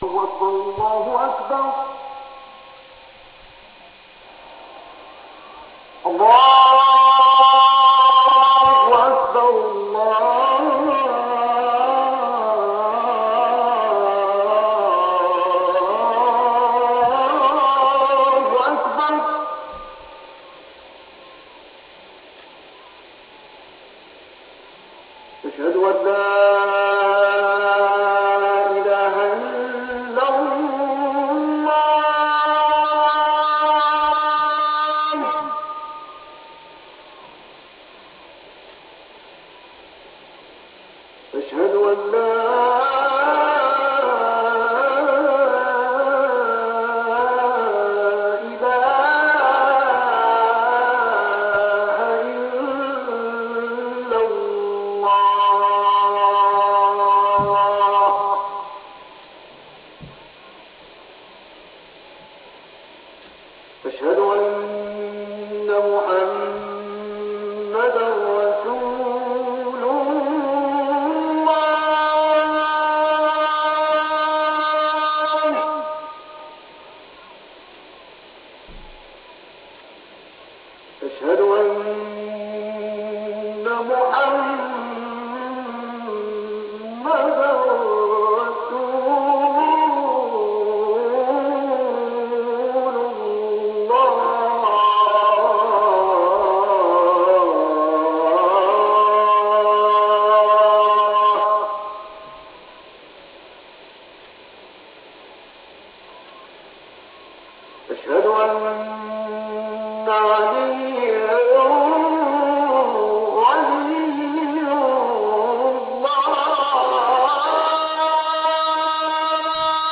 The Adhan Page is dedicated to the Adhan (Call to prayer) sound files.
Adhan from Iran, zipped 1.6M or
Adhan-Iran.wav